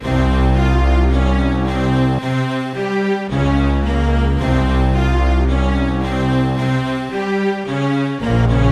另一个字符串循环
Tag: 110 bpm Hip Hop Loops Strings Loops 1.47 MB wav Key : Unknown